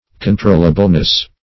Controllableness \Con*trol"la*ble*ness\, n.
controllableness.mp3